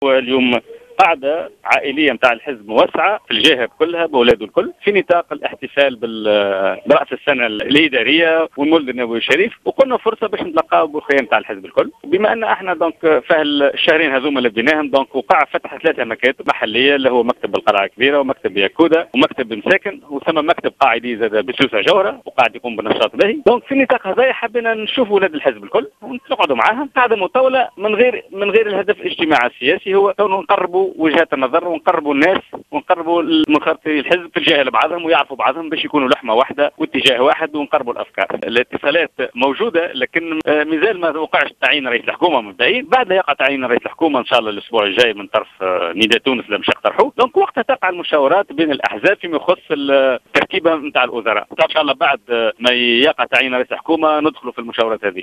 Le député d’Afek Tounes à l’assemblée des représentants du peuple, Hafedh Zouari, a indiqué ce dimanche 4 janvier 2015 dans une déclaration accordée à Jawhara FM, que sont parti entamera les négociations avec Nidaa Tounes concernant la composition du prochain gouvernement, une fois le nom du premier ministre annoncé.